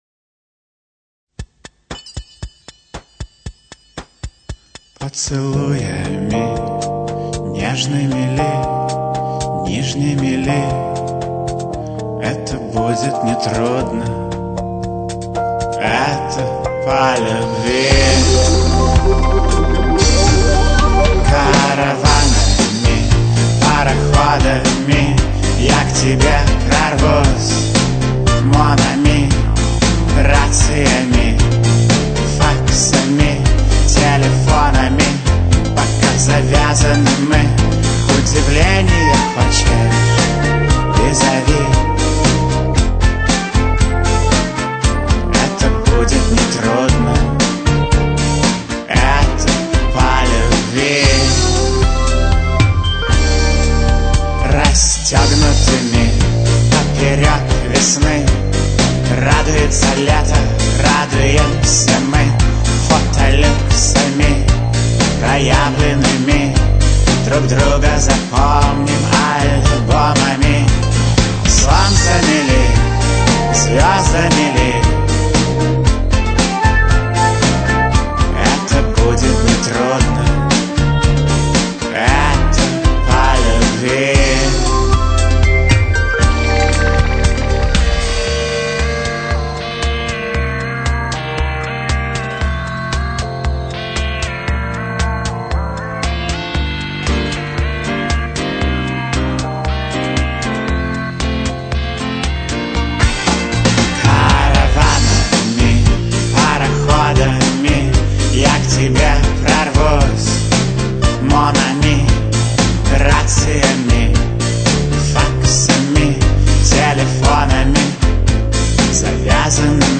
вокал
гитары, клавишные, бас, бэк-вокал
ударные, клавишные.